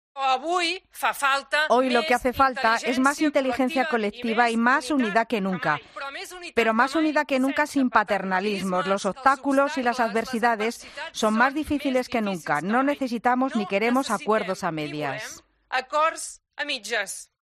Marta Rovira ha indicado, en su intervención abierta a los medios ante el Consell Nacional de ERC, que deseaba "hablar claro" y "sin paternalismos" porque está convencida de "la madurez política de la sociedad catalana" y de que ésta pueda entender perfectamente la situación actual que vive Cataluña.